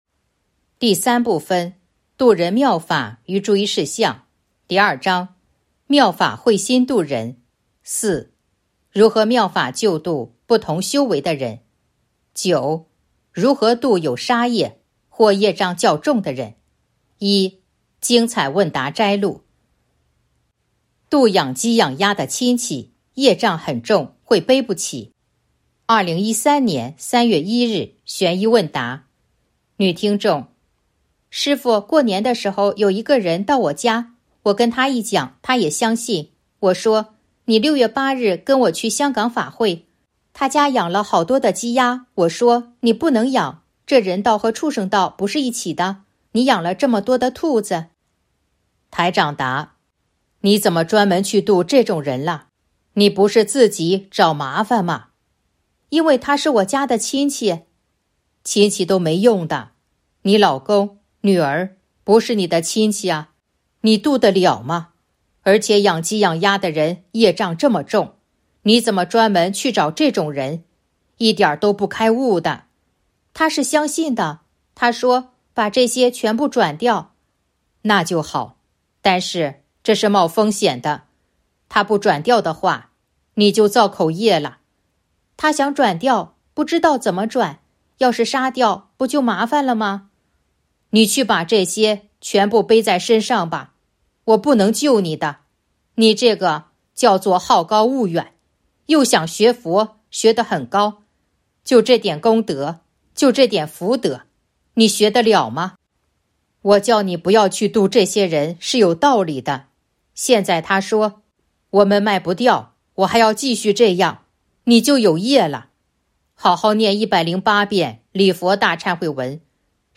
037.（九）如何度有杀业或业障较重的人 1. 精彩问答摘录《弘法度人手册》【有声书】 - 弘法度人手册 百花齐放